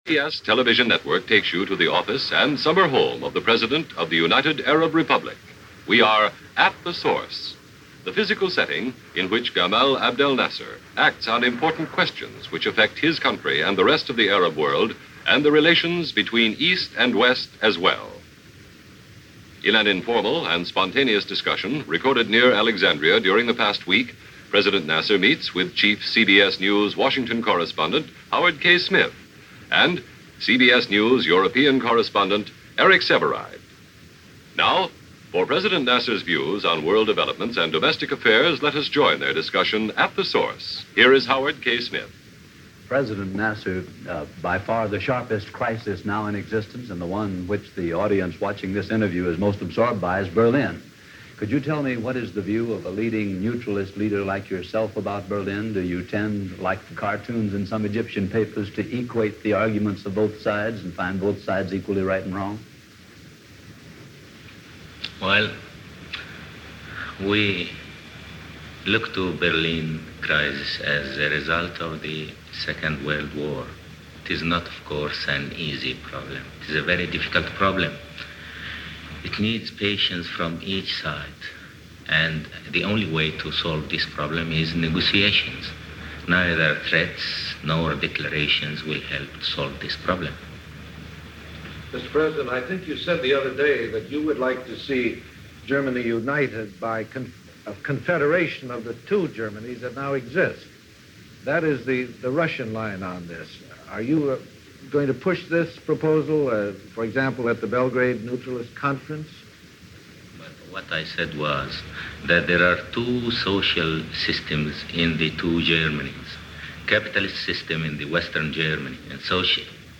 Gamal Abdel Nassar in conversation - 1961 - Past Daily After Hours